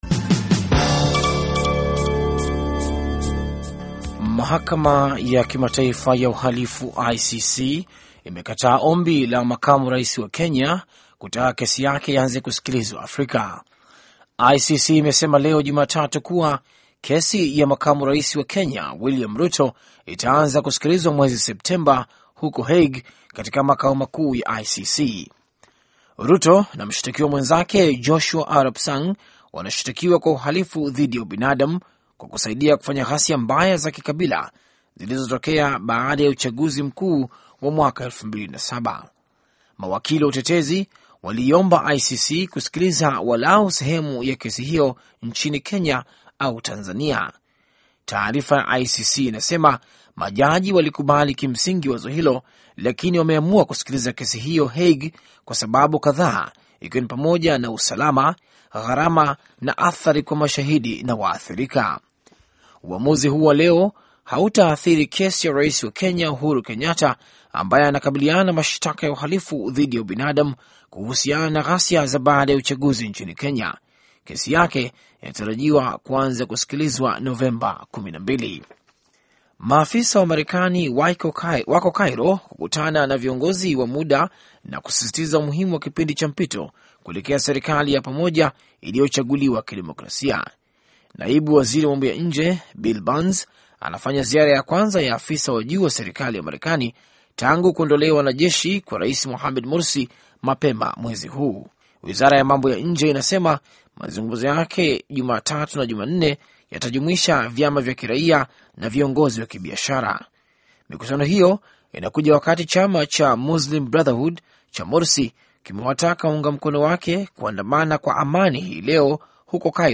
Taarifa ya Habari VOA Swahili - 7:11